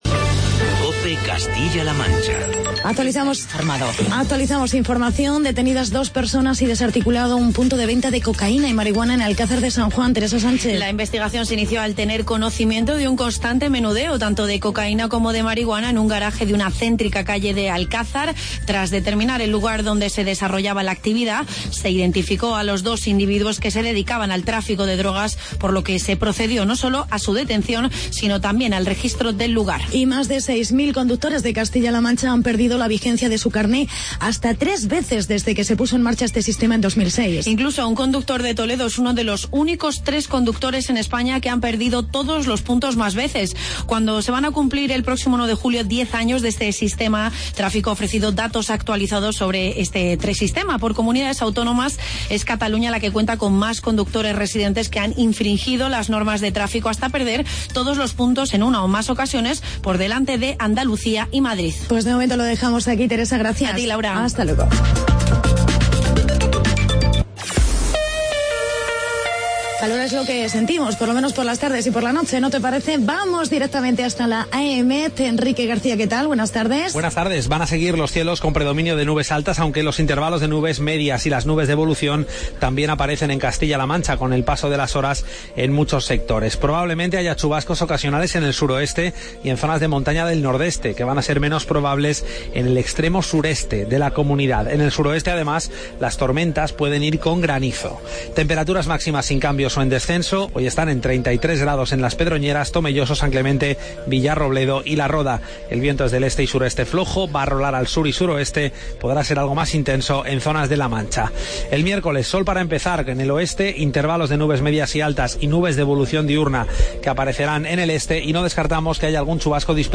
Entrevista con el Secretario General del PP de CLM, Vicente Tirado.